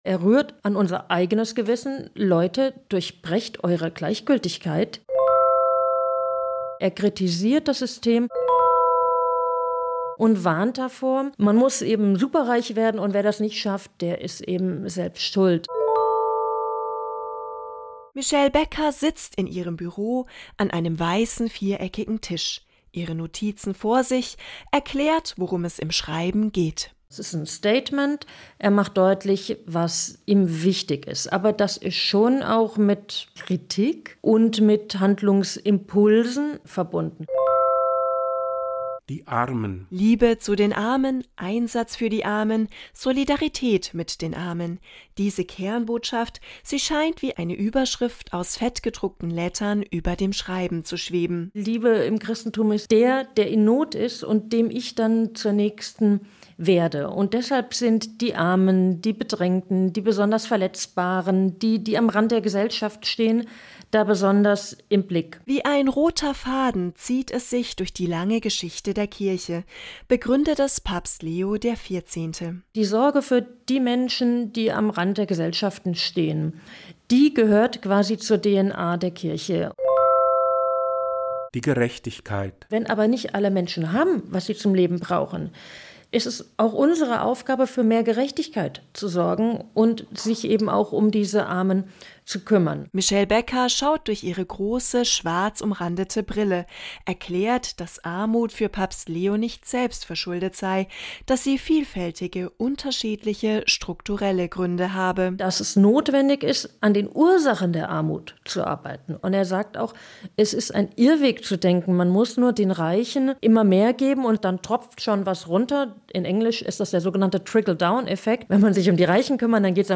interviewt.